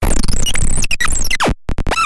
电瓶玩具猕猴桃" 鼓声低1
描述：从一个简单的电池玩具中录制的，是用一个猕猴桃代替的音调电阻！
Tag: 音乐学院-incongrue 电路弯曲 俯仰